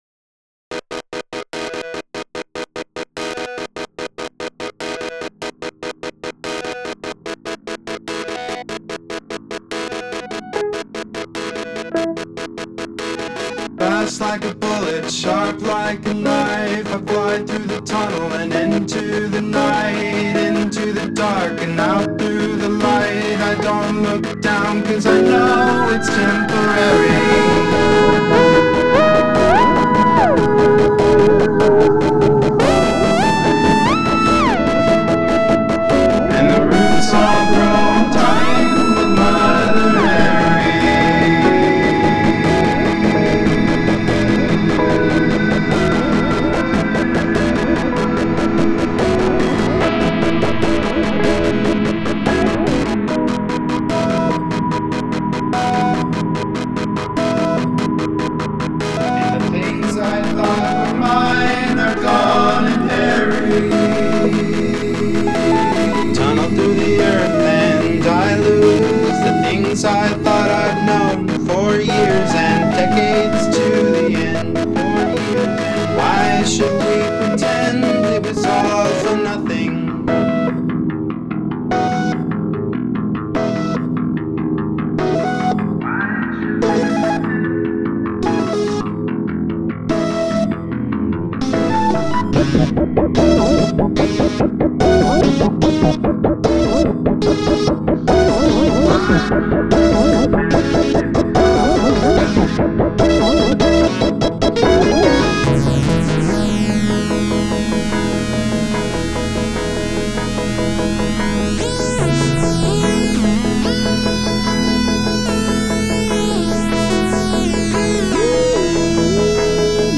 And then, you heat it up with some great drums.